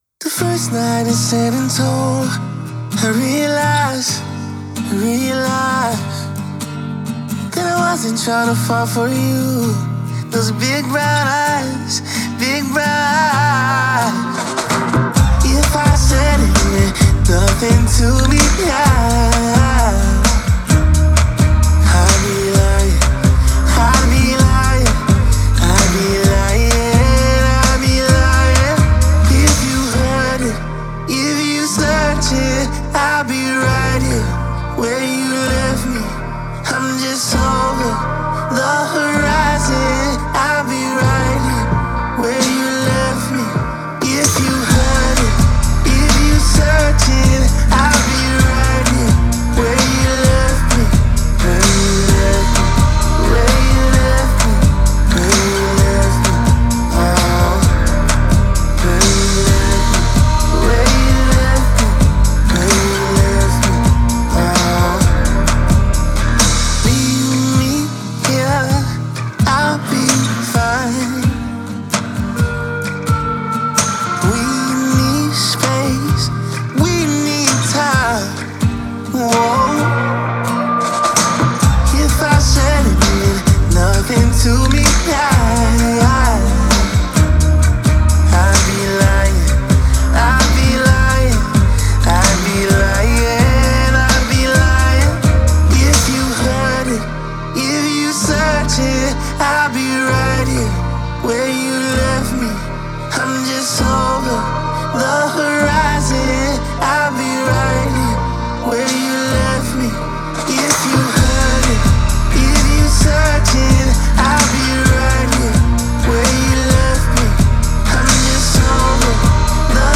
создавая атмосферу легкости и свободы.